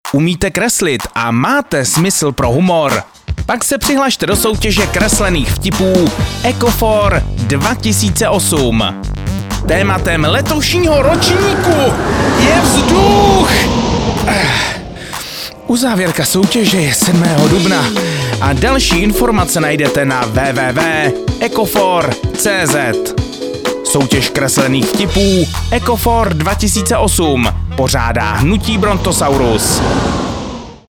Spot do rádií pro ročník 2008